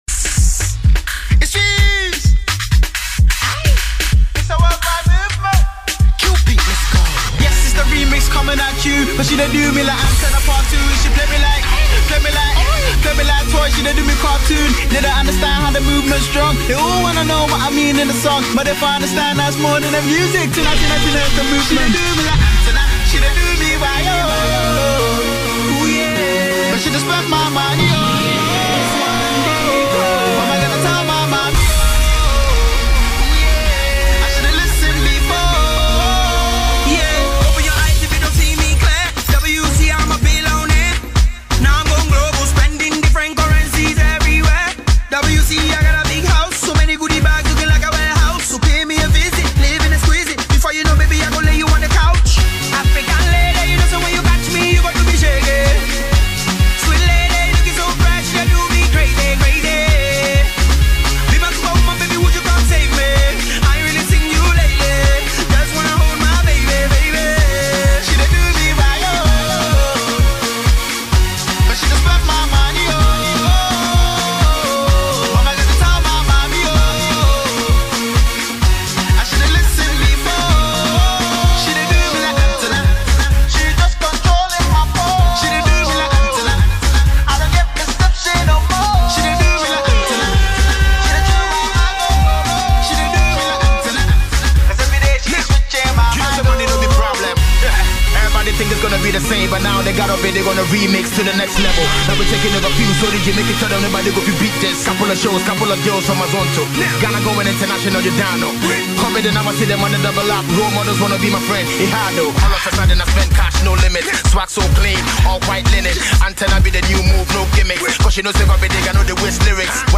Afrobeat remix